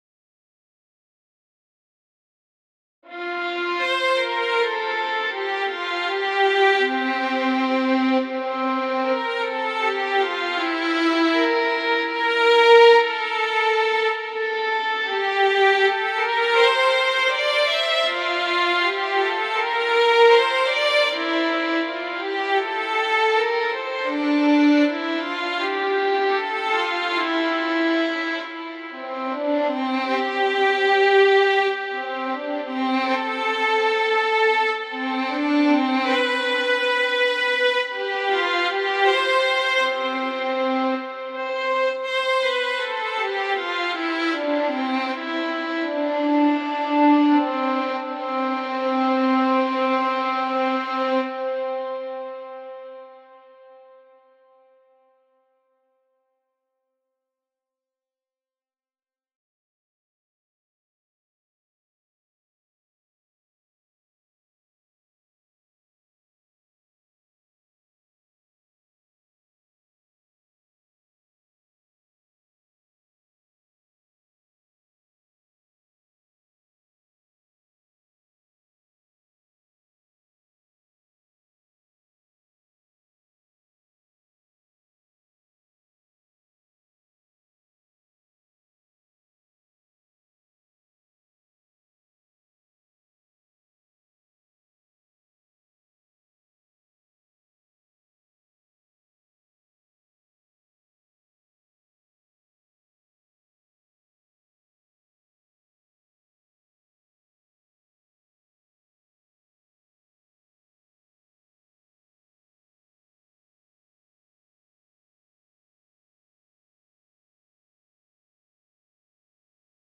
Partitura do segundo movemento (Largo, fragmento) do concerto "O inverno" ("As catro estacións", A. Vivaldi)
Actividade 3: Tocar a melodía da voz 1, xunto co violín, que soa a tempo, a unha velocidade de negra= 80.